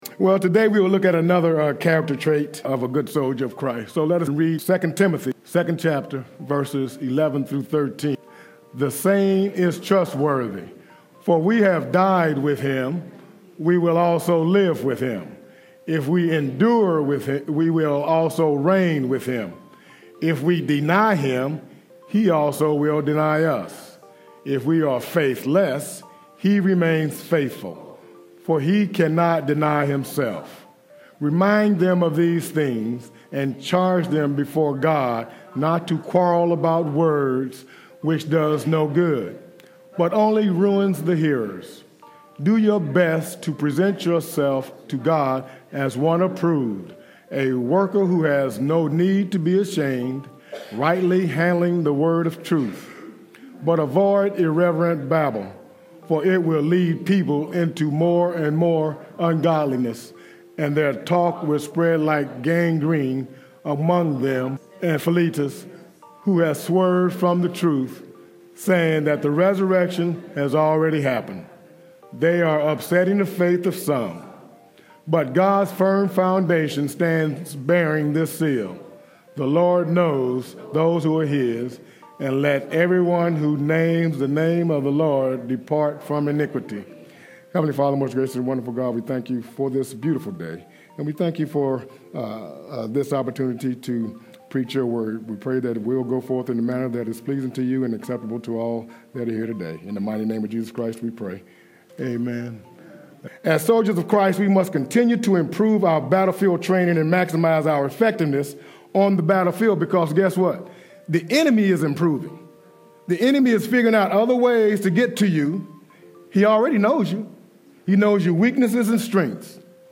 admin 2 Timothy, characteristics, Sermon, soldier April 27, 2020